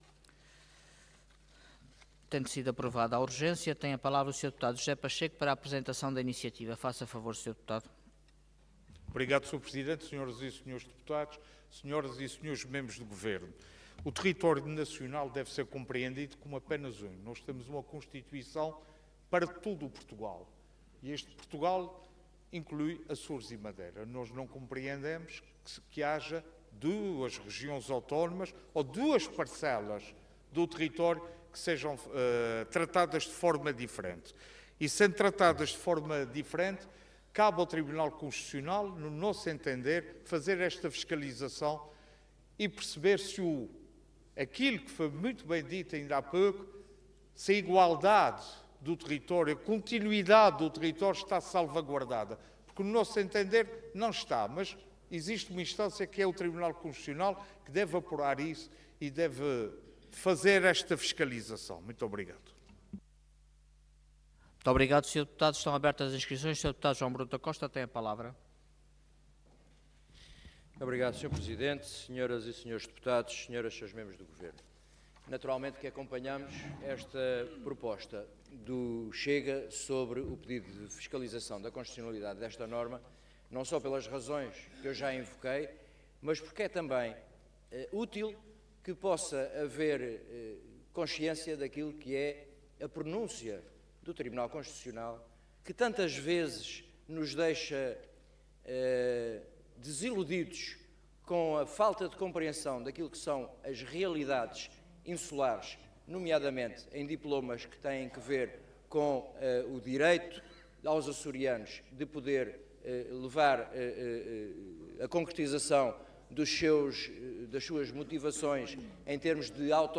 Website da Assembleia Legislativa da Região Autónoma dos Açores
Intervenção
Orador José Pacheco Cargo Deputado